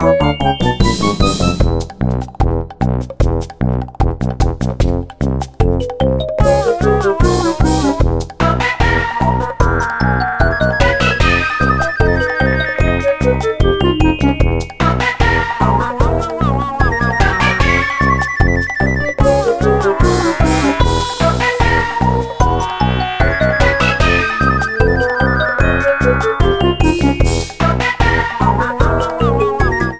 Castle boss battle music